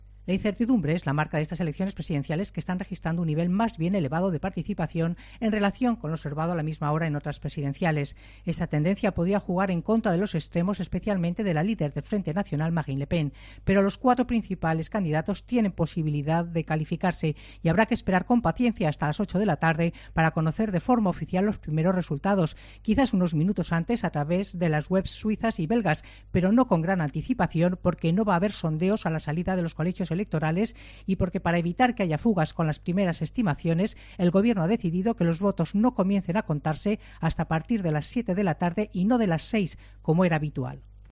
Alta participación en las presidenciales francesas. Crónica